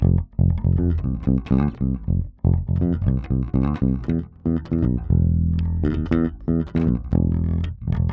28 Bass PT3.wav